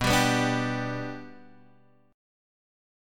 Bm#5 chord